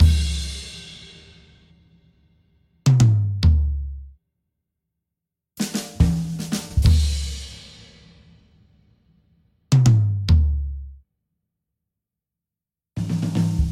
几个啤酒瓶碰在一起
描述：几个啤酒瓶碰在一起
Tag: 瓶子 啤酒 玻璃 碰撞